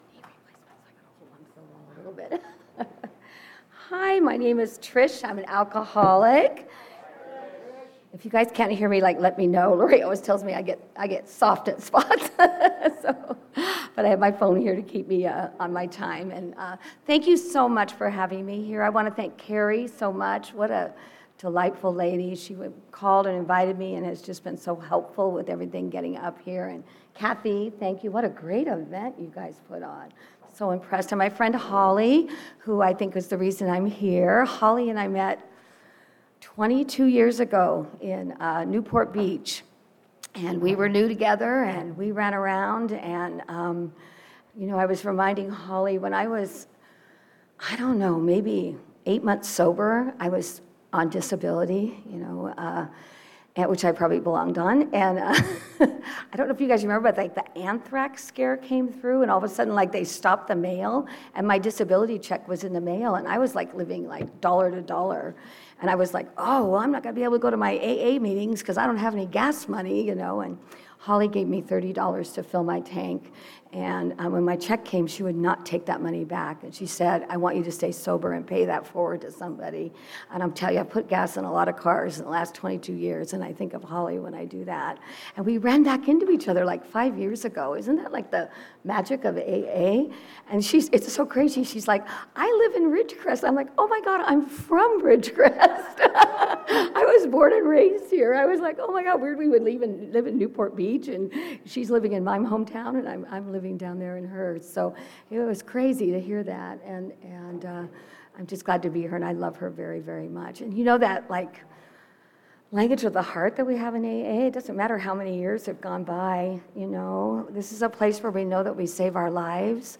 32nd Indian Wells Valley AA Roundup